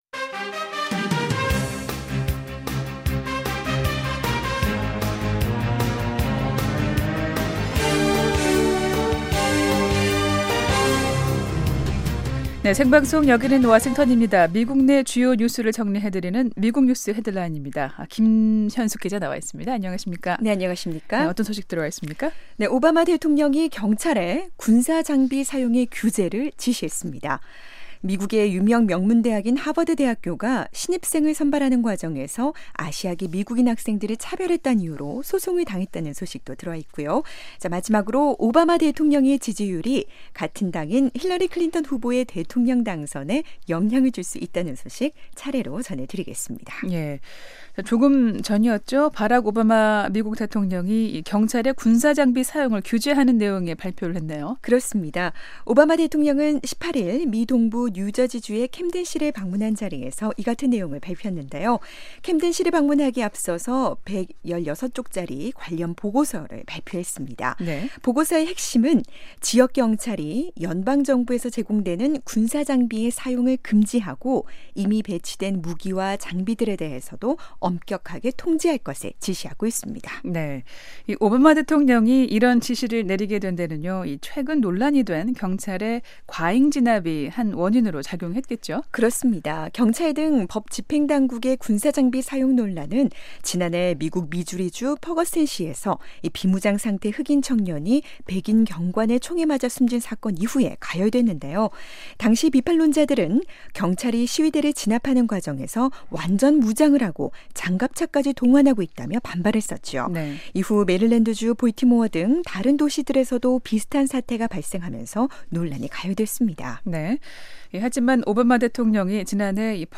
미국 내 주요 뉴스를 정리해 드리는 ‘미국 뉴스 헤드라인’입니다. 오바마 대통령이 경찰에 군사 장비 사용의 규제를 지시했다는 소식 들어와있습니다.